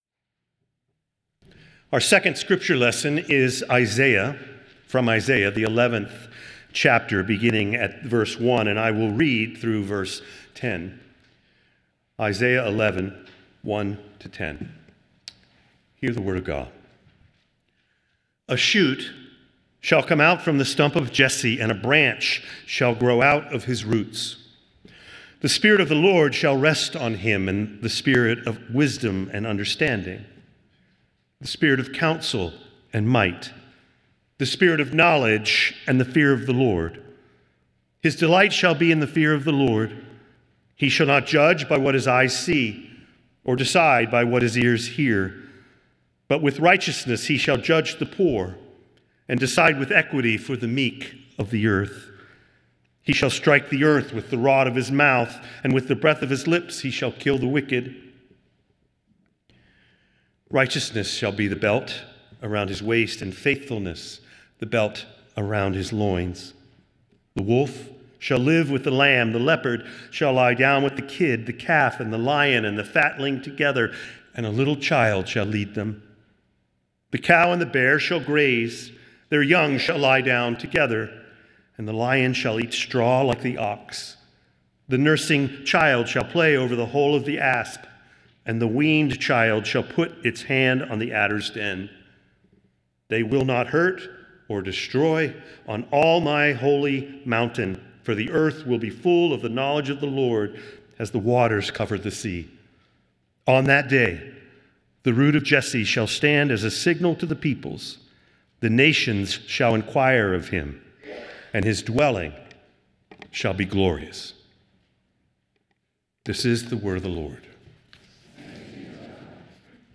Nassau Presbyterian Church Sermon